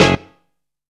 MELLO HIT.wav